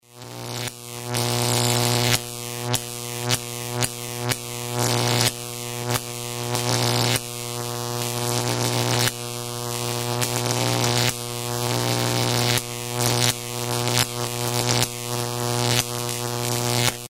Замыкает проводка